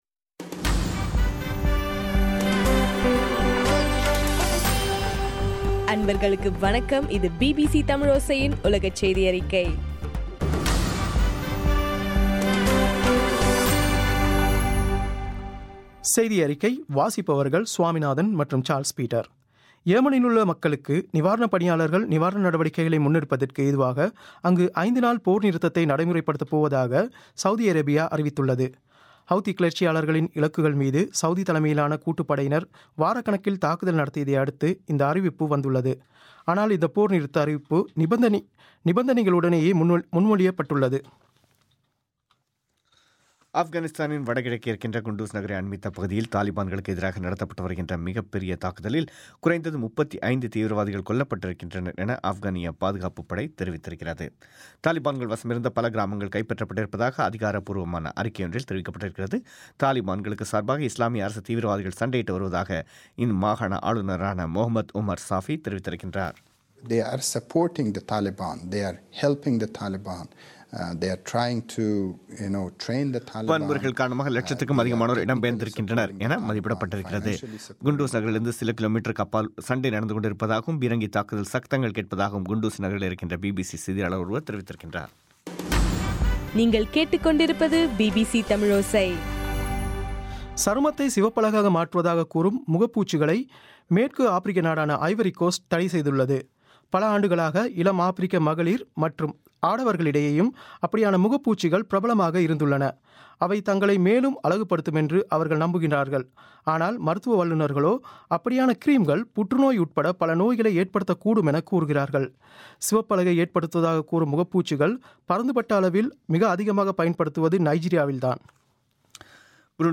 மே 7 பிபிசியின் உலகச் செய்திகள்